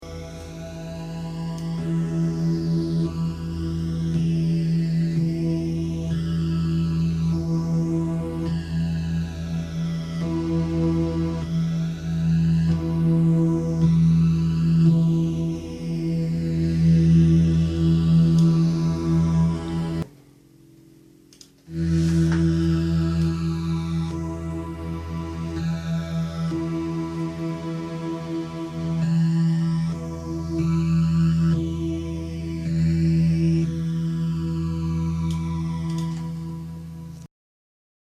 ich benutze meine vst plugins für den test. leider kenn ich mich mit REW nicht aus. also ich hab jetzt verschiedenes ausprobiert und von der OUT buchse 1 kommt definitiv ein schwächerer sound raus...
Durch die erste buchse hört man nur den bass und durch die 2. den bass und noch das geräusch der Seite wie sie gezupft wird. hier habe ich eine mp3 wo ich immer von rechts nach links switche durch den panoramaregler oder bilde ich mir nur was ein und es ist standart bei jedem so? man hört schon ein unterschied oder?